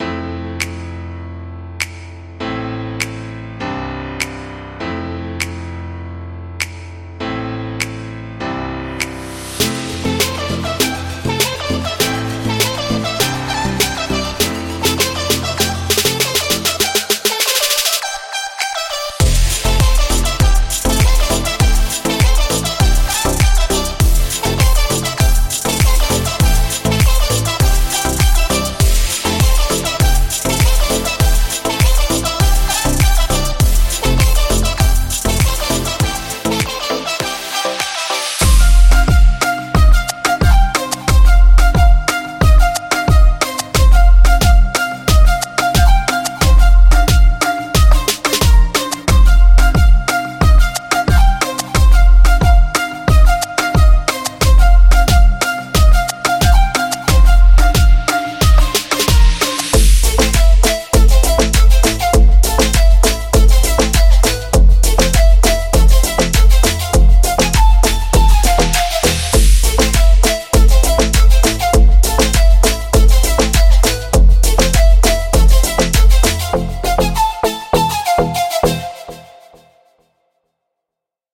人惊叹的系列，其中包含各种Tropical Pop乐句和样本。
栎，闪闪发光的弹奏声，深沉的低音凹槽，奇异的旋律,鼓声强劲的鼓凹槽等等。
循环范围从90到115 BPM,包括低音,鼓，主音和旋律。
Moombahton, Reggaeton, Future Bass, House等。